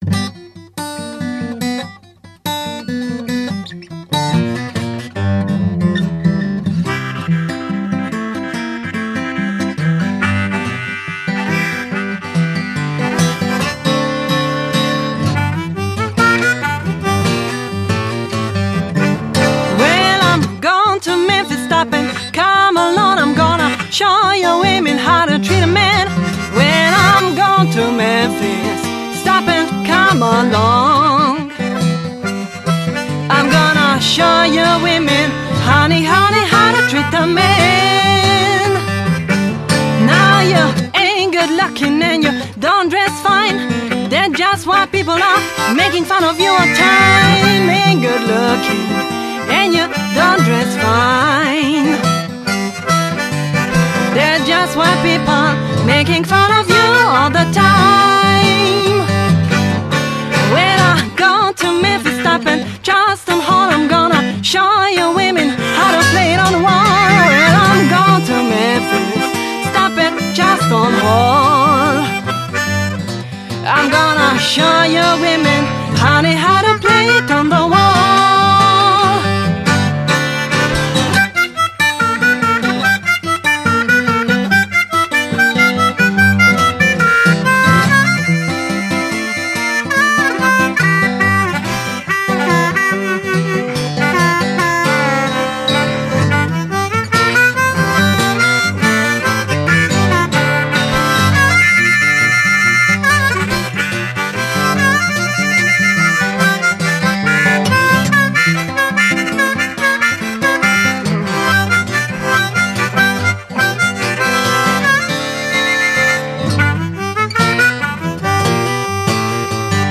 finest acoustic blues
śpiew
gitara
harmonijka